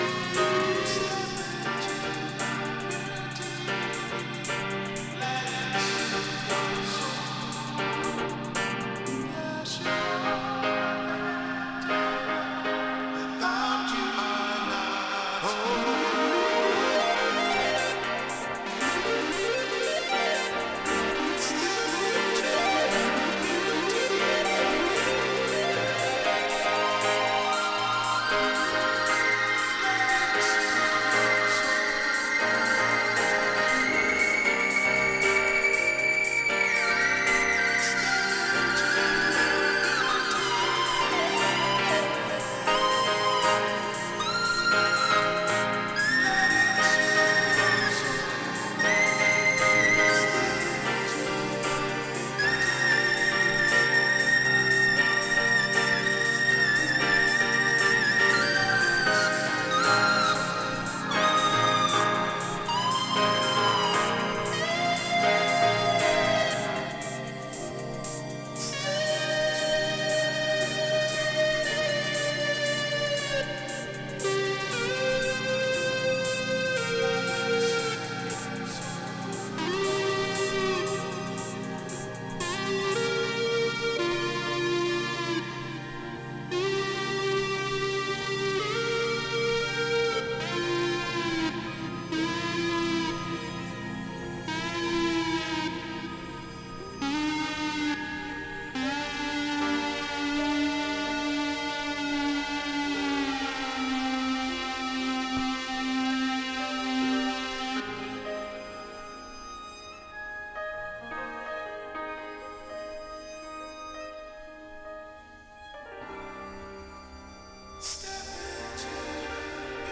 B-2にはそのビートレス・バージョンまで収録。